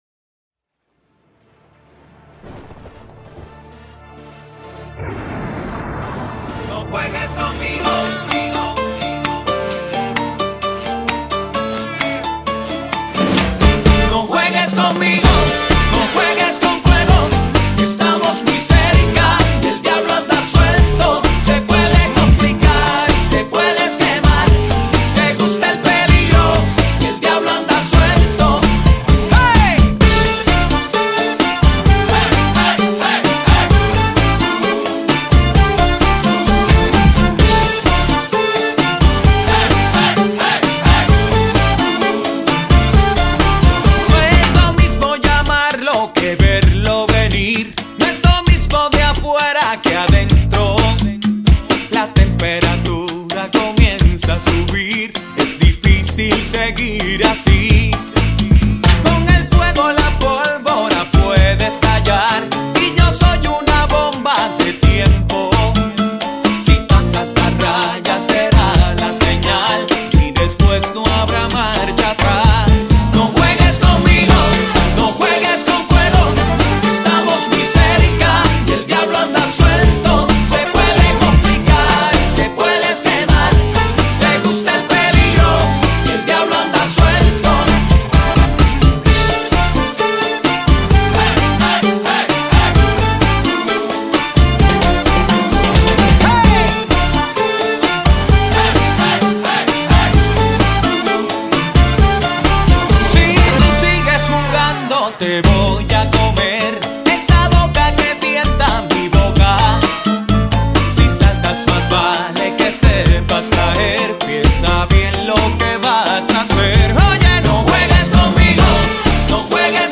DISFRUTA DE LA SALSA INTERNACIONAL!